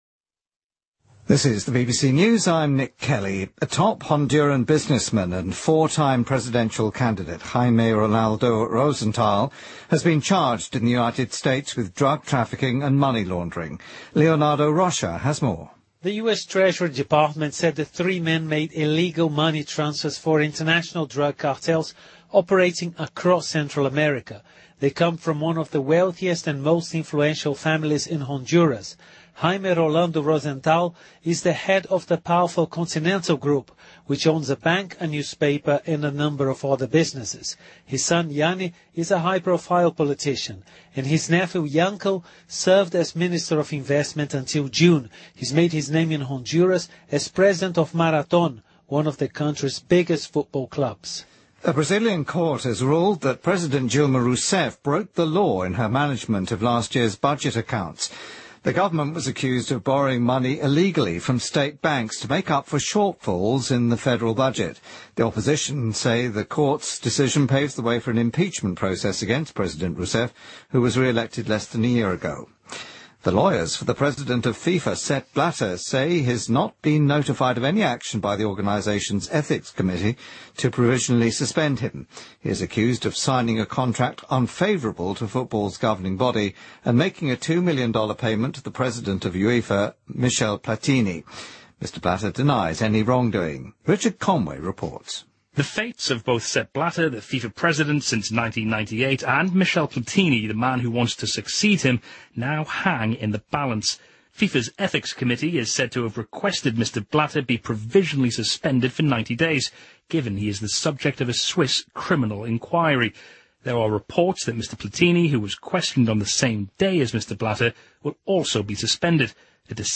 BBC news:奥巴马就美军误炸医院道歉|BBC在线收听
BBC news,奥巴马就美军误炸医院道歉